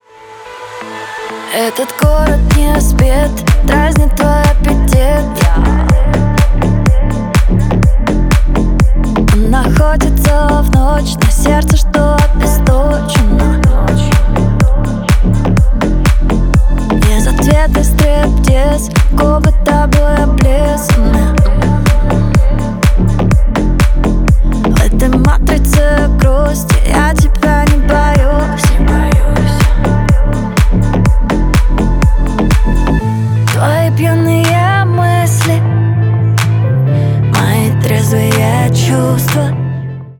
клубные
поп